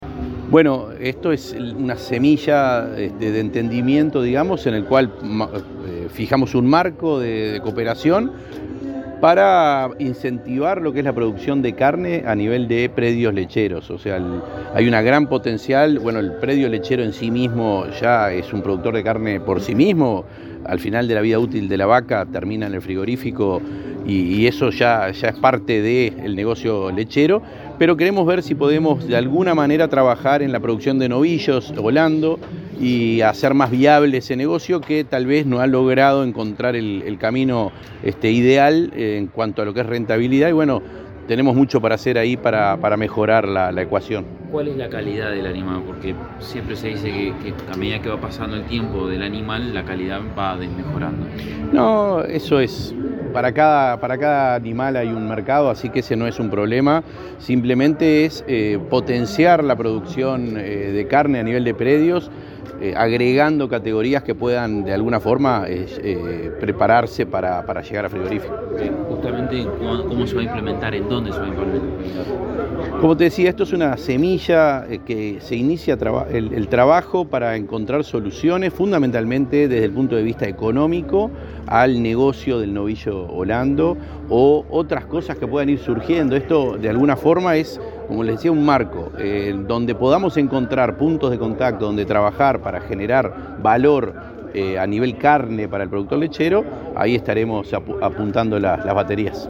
Declaraciones del presidente del INAC, Conrado Ferber